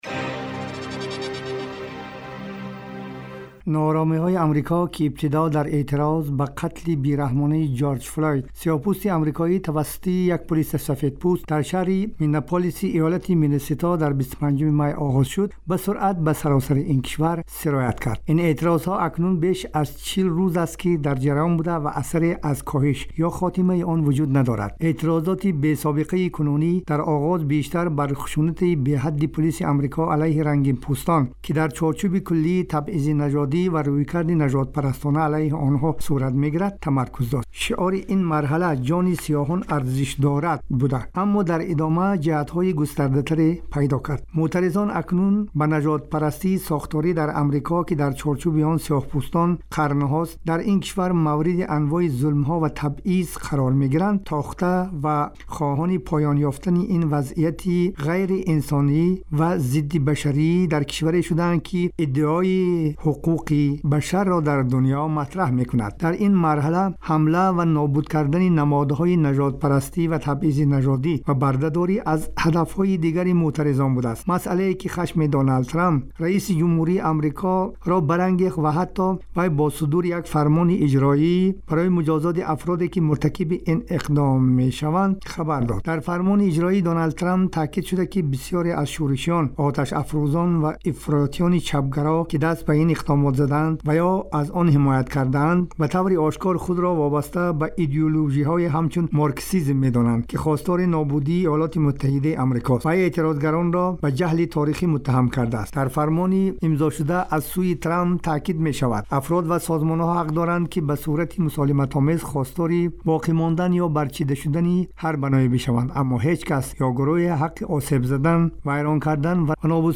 گزارش ویژه : ادامه اعتراضات و ناآرامی های آمریکا